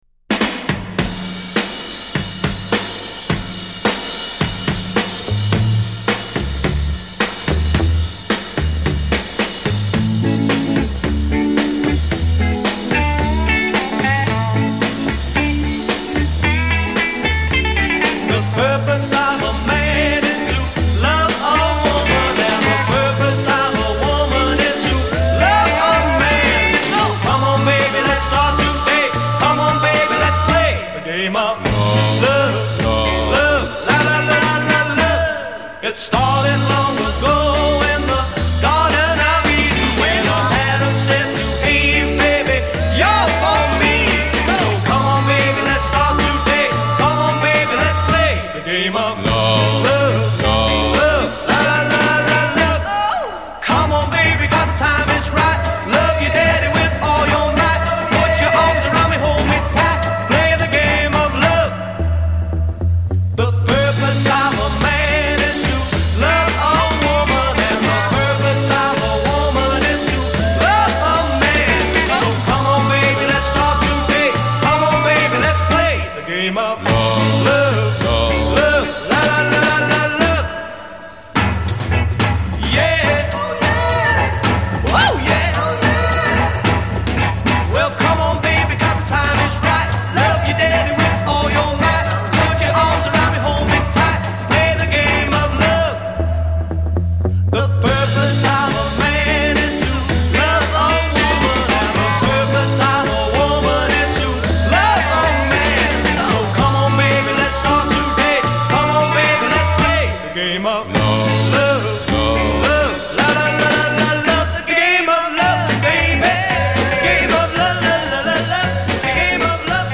bass guitar
drums
with unidentified backing vocals.
intro 0:00 16 variation on the "Louis, Louis" progression
A' coda : 16+ double time rhythm, repetition of hook text